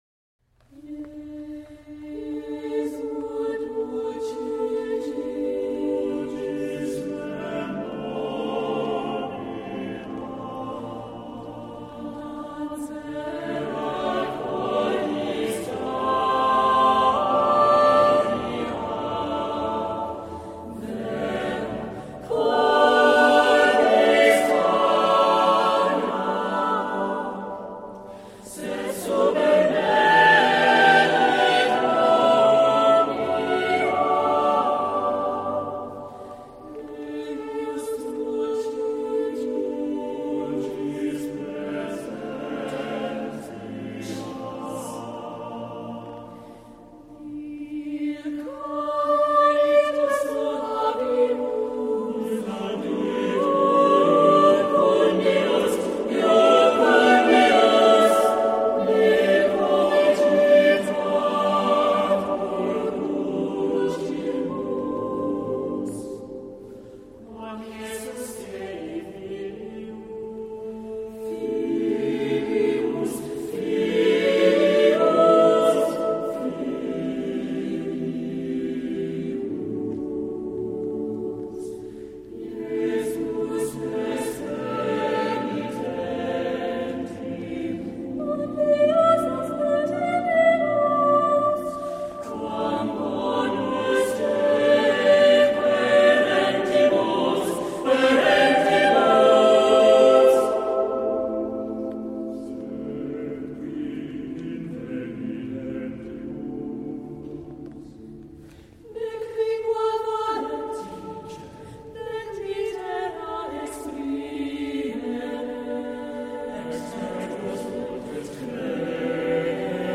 Voicing: SSATTB